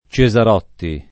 [ © e @ ar 0 tti ]